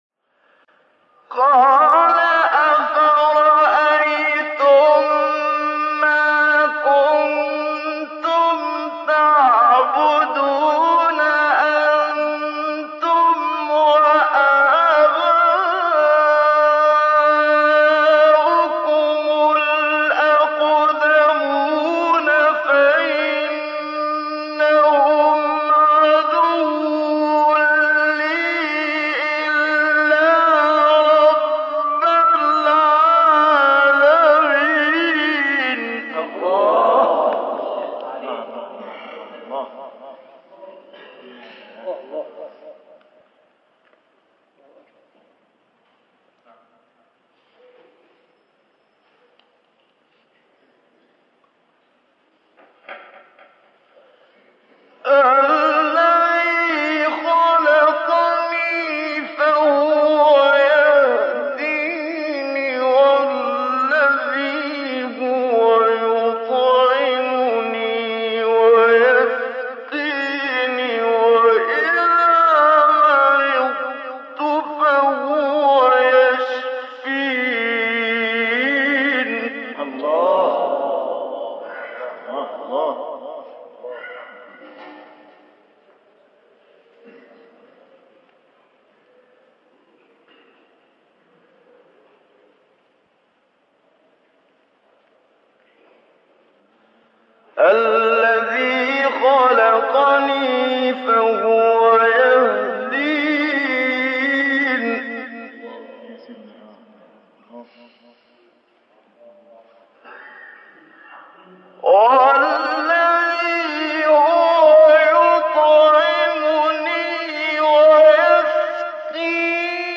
سوره : شعراء آیه : 75-89 استاد : محمد صدیق منشاوی مقام : رست قبلی بعدی